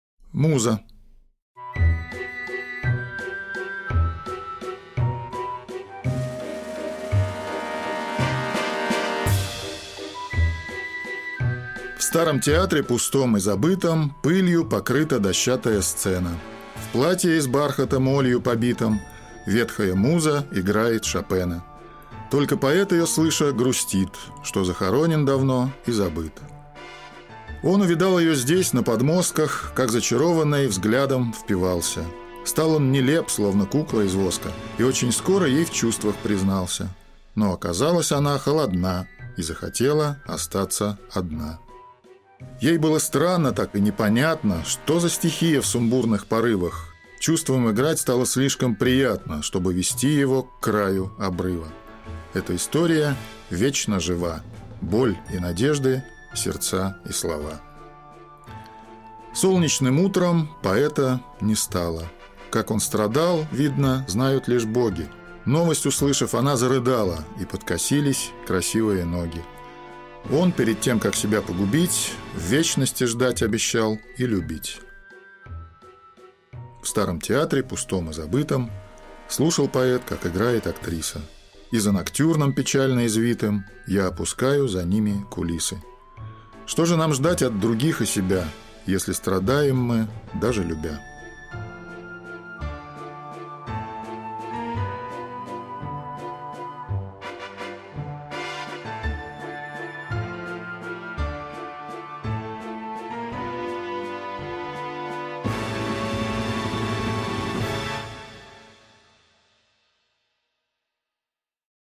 Аудиокнига Отблески снов. Сборник | Библиотека аудиокниг